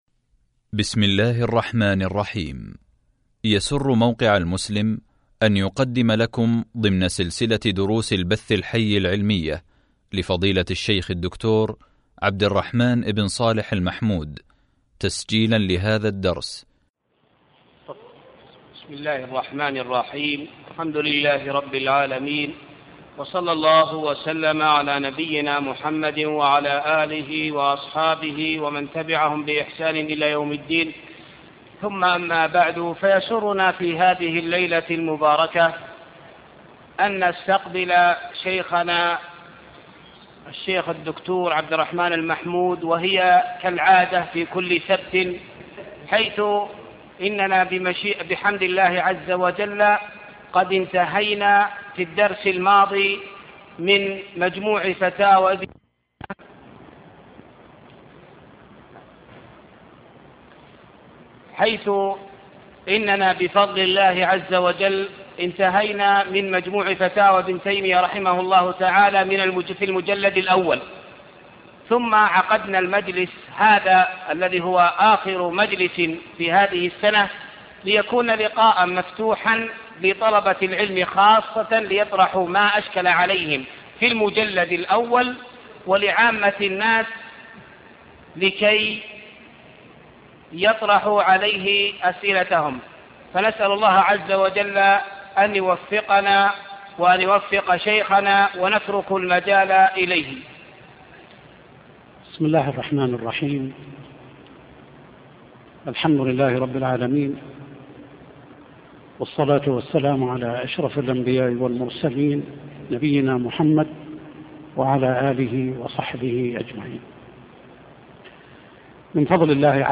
فتاوى ابن تيمية - الدرس السادس عشر (لقاء مفتوح)